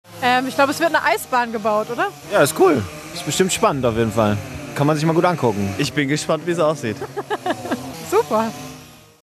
eisbahnpassanten.mp3